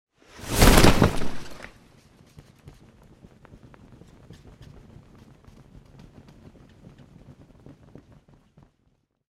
Звуки прыжка с парашютом
Звуковой сигнал в самолете перед прыжком